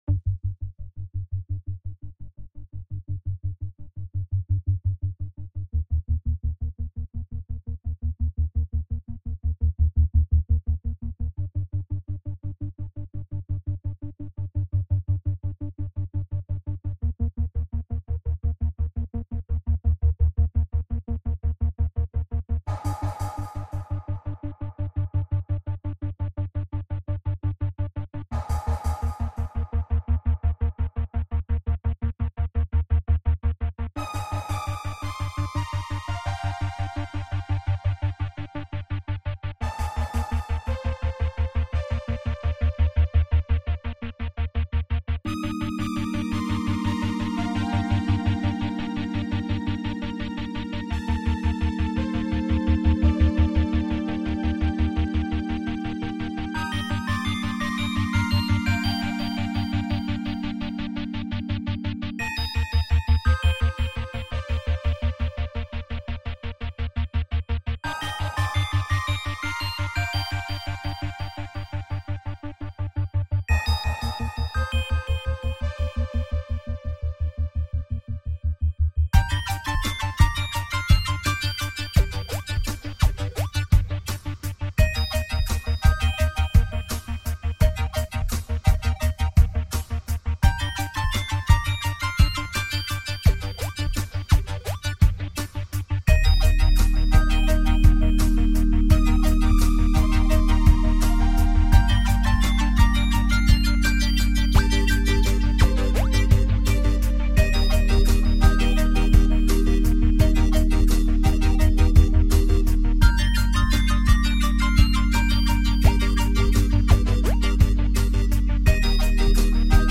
It's very experimental, and there's no vocals.